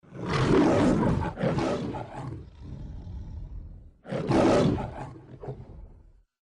lion-roar.mp3